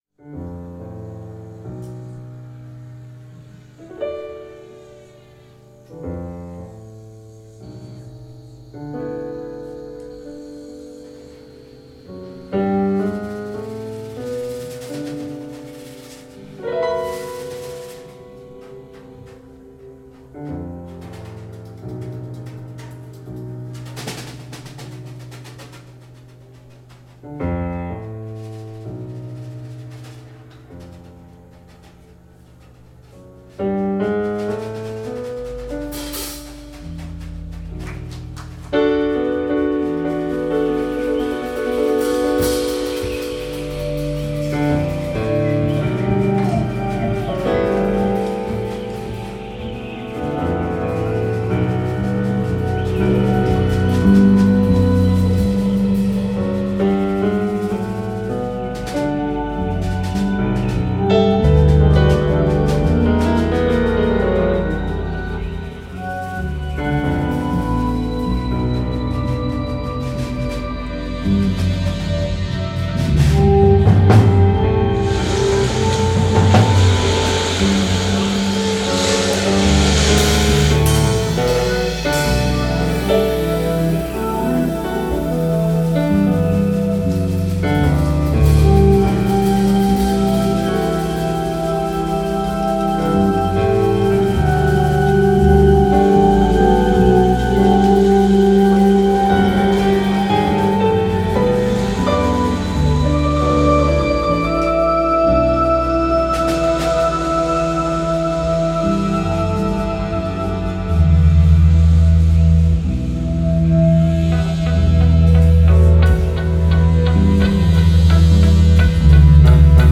Keyboard/Synthesizer
Flügelhorn/E-Gitarre
Bass/Samples
Schlagzeug